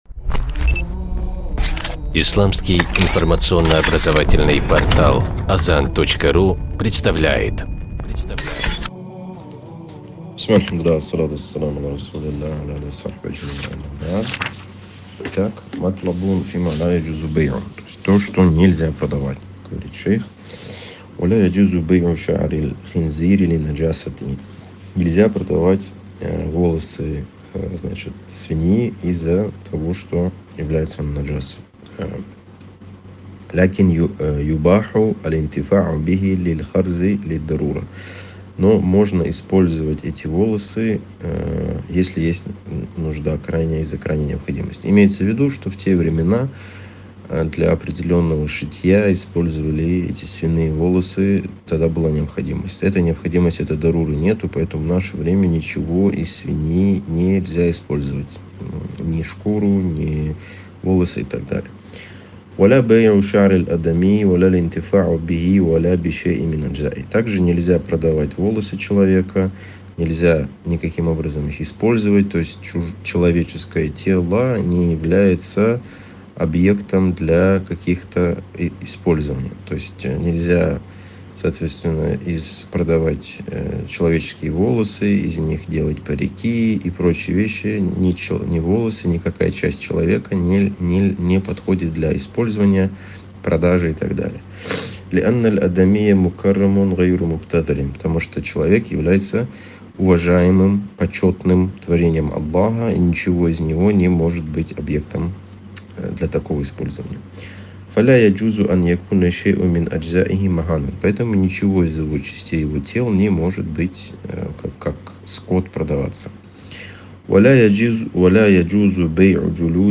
Цикл уроков: «Харам и халяль» по книге «Хазр валь-Ибаха»